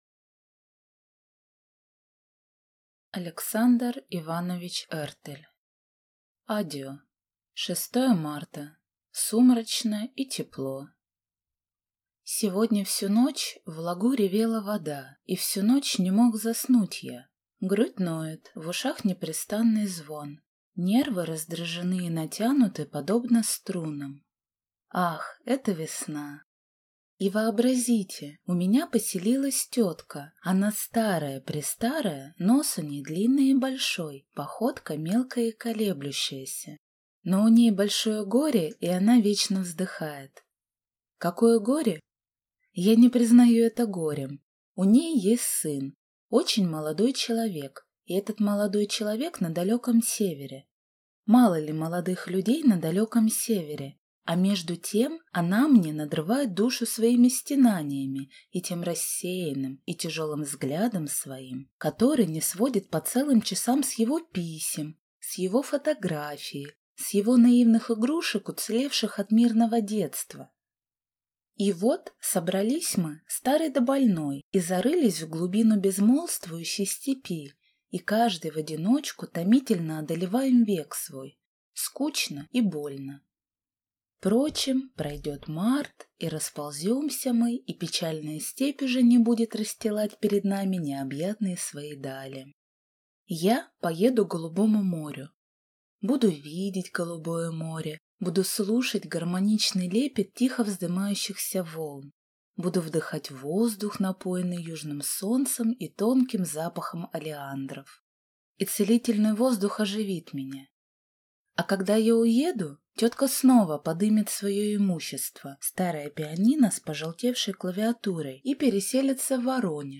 Аудиокнига Addio | Библиотека аудиокниг